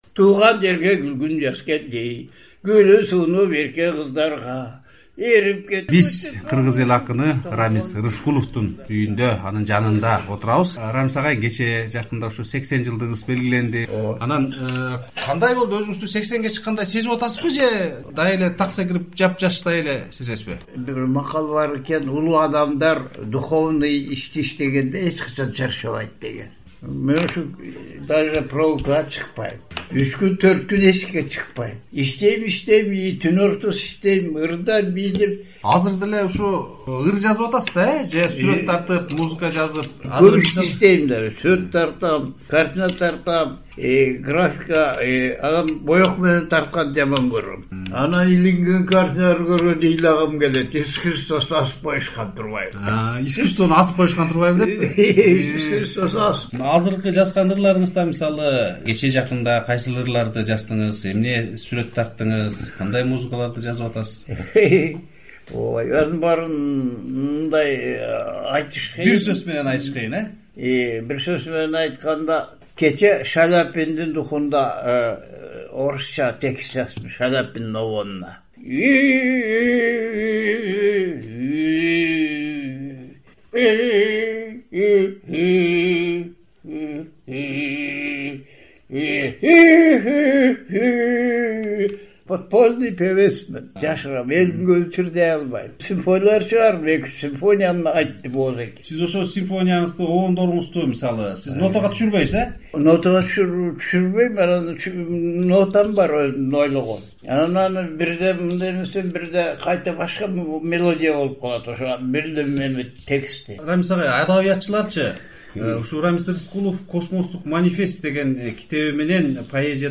Кыргыз эл акыны, сүрөтчү Рамис Рыскулов 26-январдын түнүндө 87 жашында дүйнөдөн кайтты. "Азаттык" радиосу акындын архивде калган акыркы маегин сунуштайбыз.